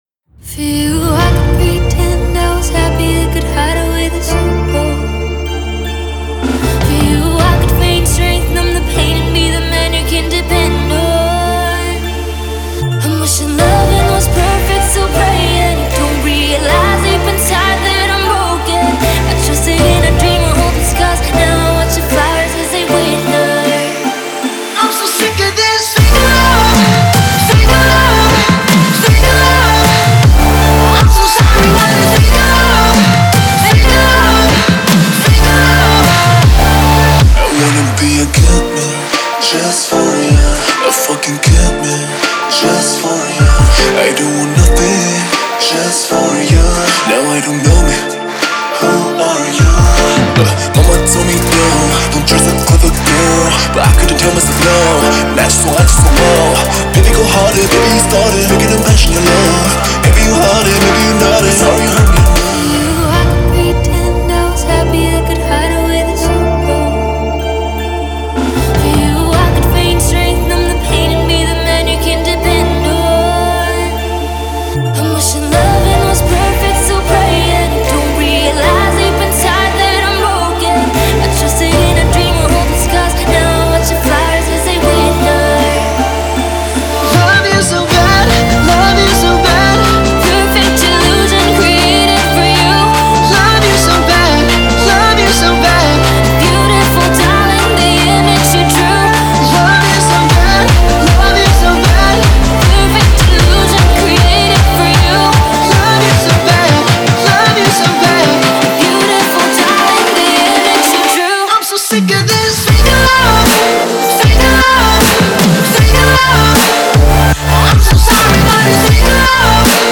выполненная в жанре поп с элементами EDM.